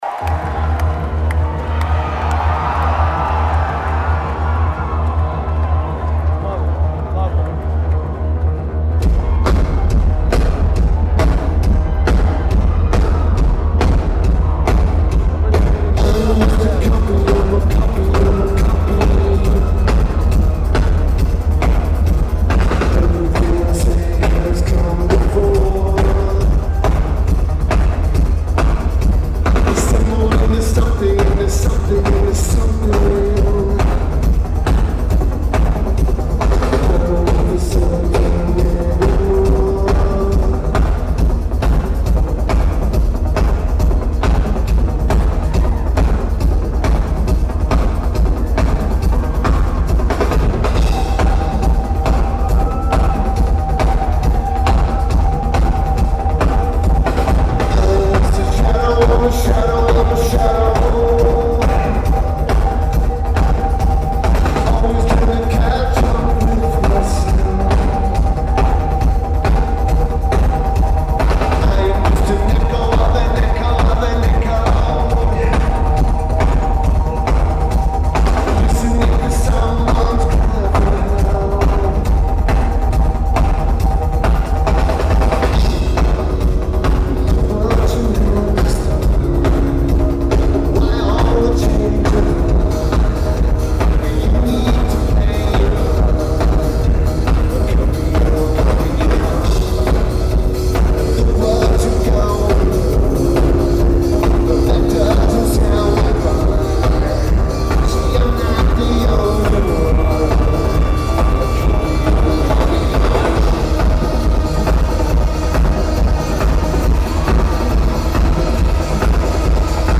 Wang Theatre at Boch Center